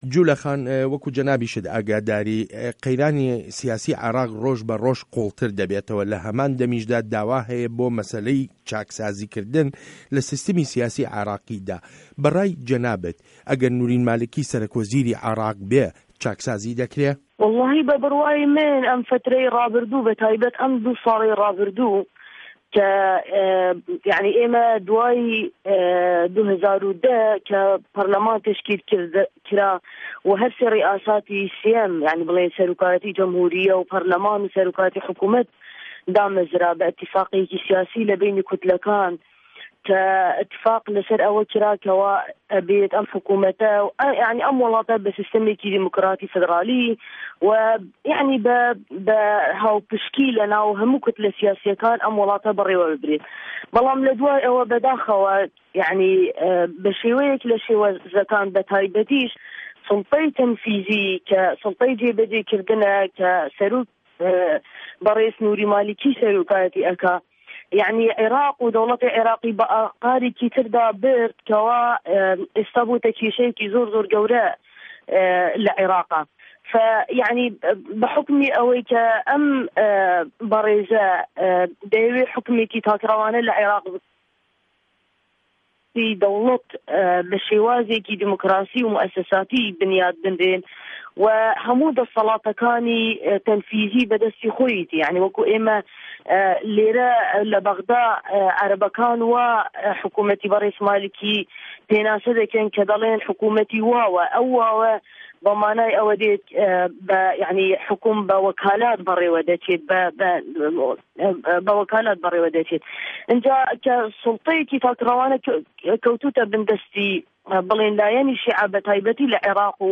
وتووێژ له‌گه‌ڵ جوله‌ حاجی